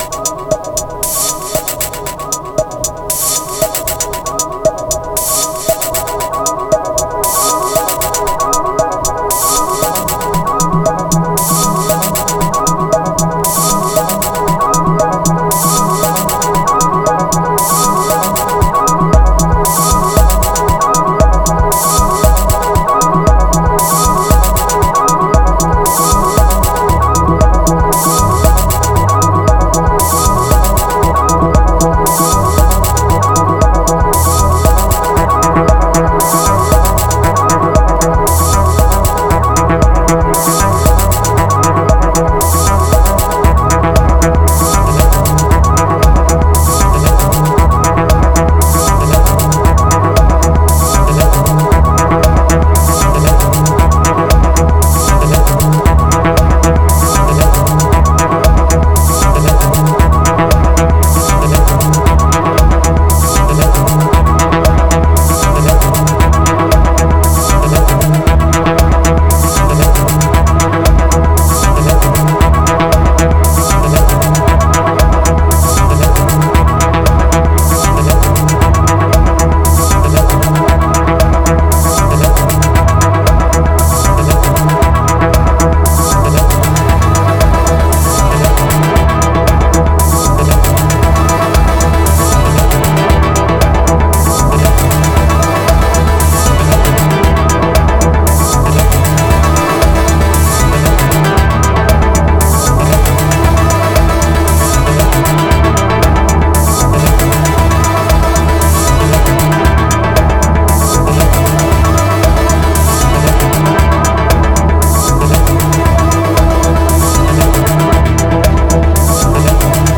456📈 - 93%🤔 - 116BPM🔊 - 2025-08-12📅 - 623🌟